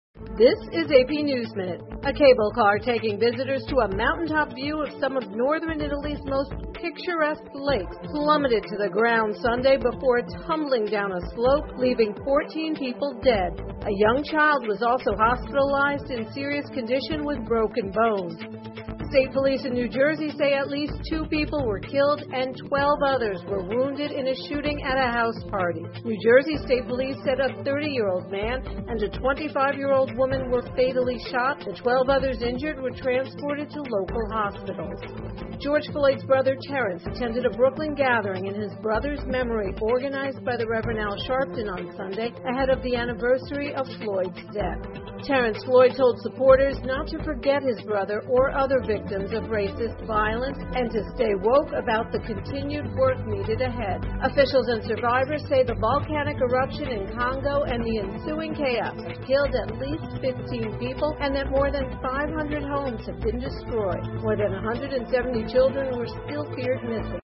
美联社新闻一分钟 AP 意大利一观光缆车从高空坠落 听力文件下载—在线英语听力室